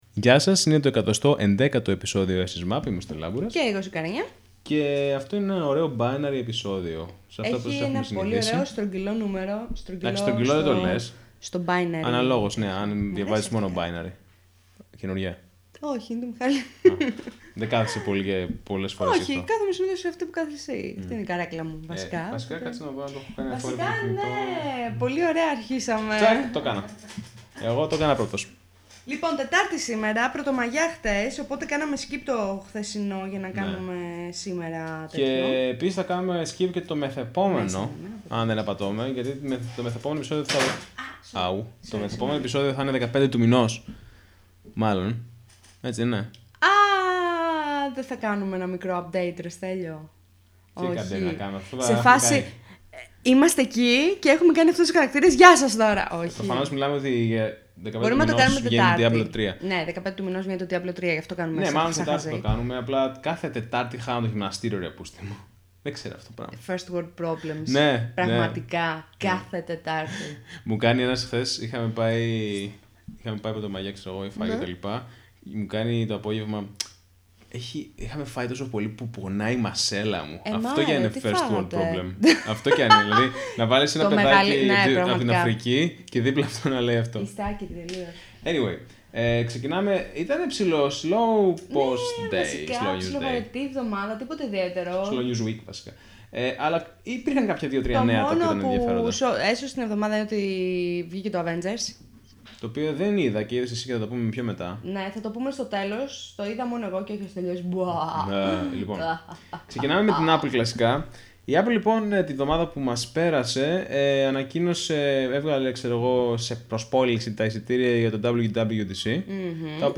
Κάθε Τρίτη βράδυ χρησιμοποιούμε το Livestream για να γράψουμε live το εβδομαδιαίο επεισόδιο,οπότε κάντε bookmark τη σχετική σελίδα.